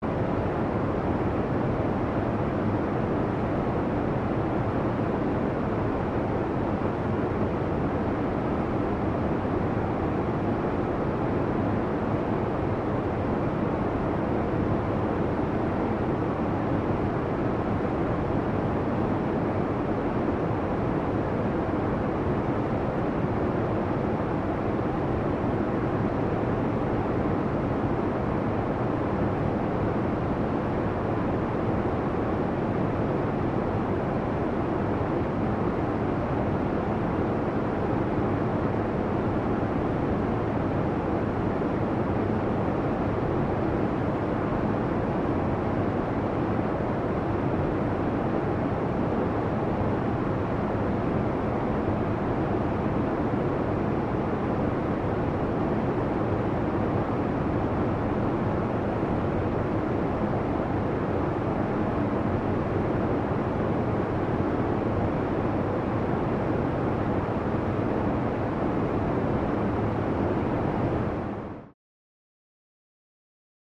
Room Ambience; Parking Garage Air Conditioner 2 No Traffic